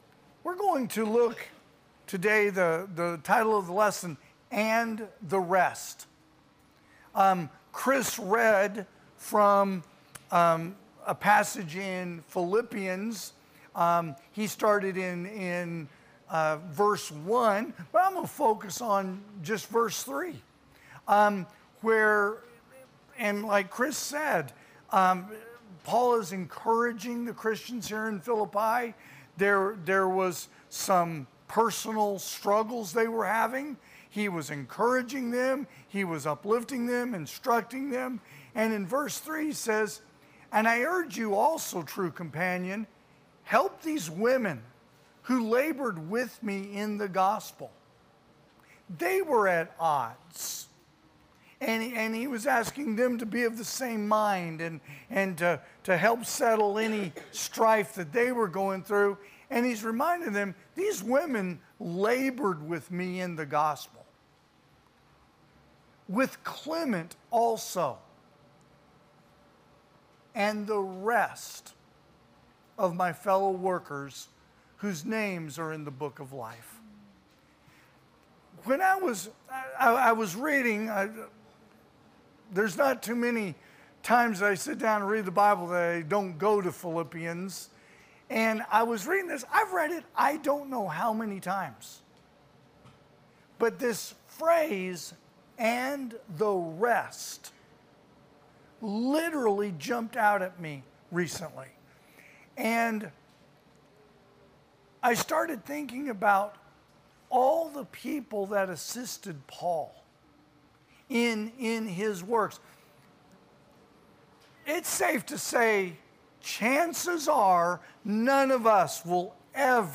2024 (AM Worship) "And The Rest"
Sermons